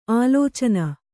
♪ ālōcana